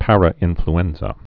(părə-ĭnfl-ĕnzə)